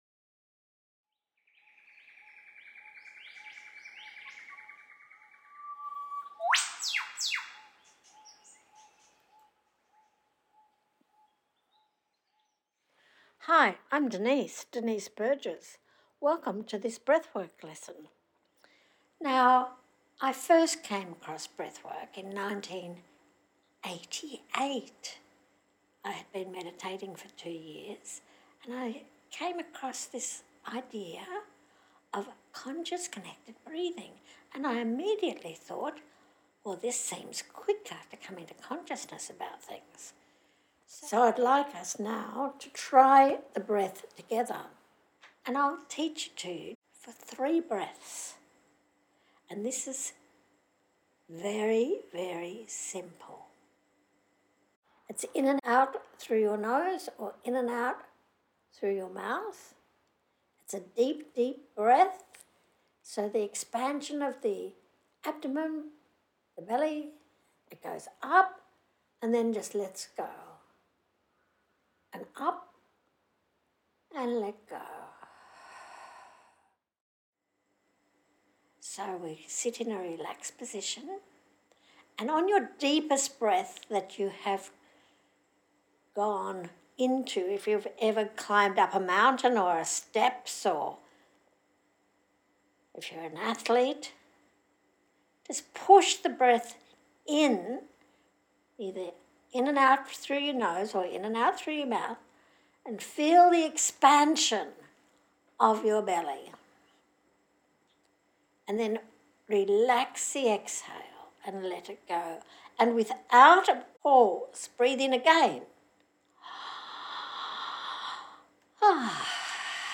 Access Your Free Breathwork Lesson Now